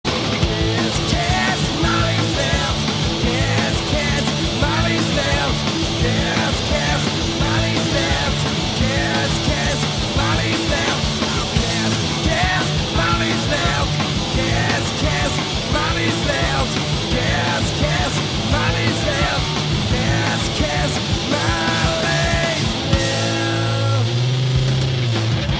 Le son n'est pas le meilleur, mais reste très bon.